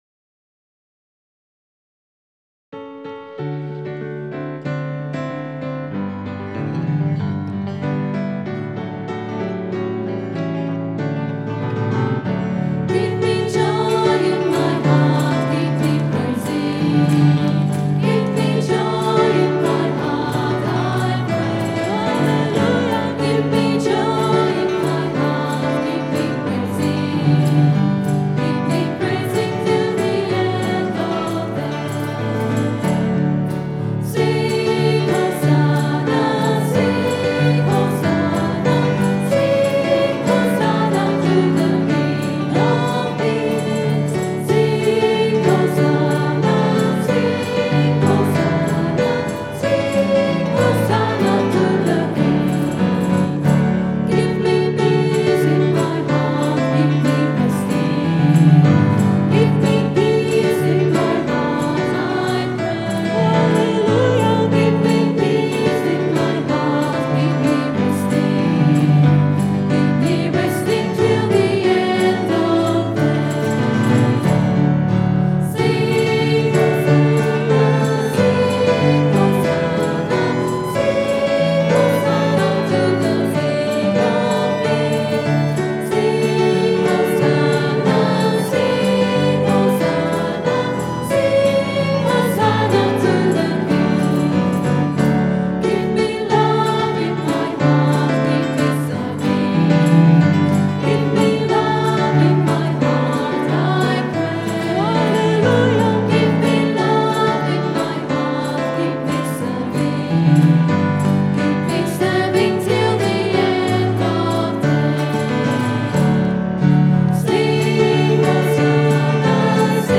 A well known handclapper of old. This song was recorded at 10am Mass on Sunday 6th July 2008. Recorded on the Zoom H4 digital stereo recorder through a Behringer SL2442FX mixer. Sound editing and effects using Acoustica Mixcraft 4 audio processing software.